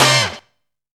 KWIK HIT.wav